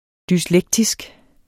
Udtale [ dysˈlεgtisg ]